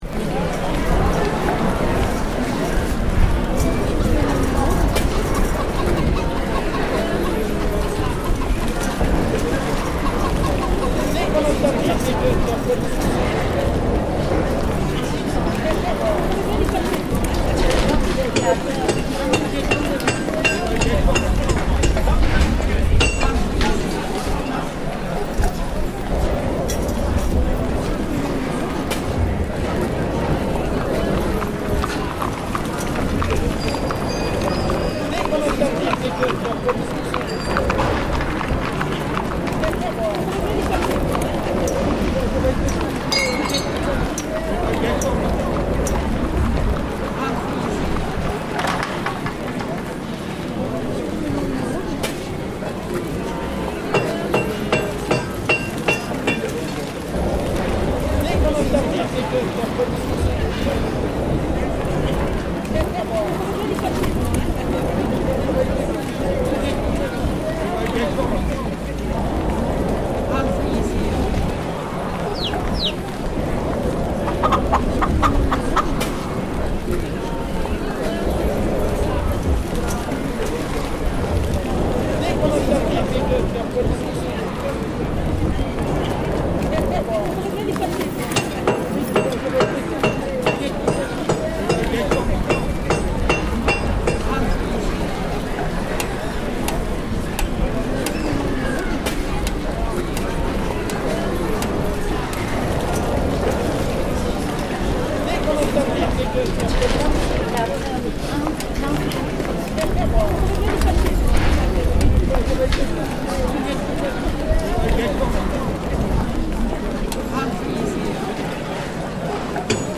village medieval 1h ambiance jdr.mp3
village-medieval-1h-ambiance-jdr.mp3